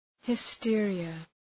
Προφορά
{hı’stıərıə}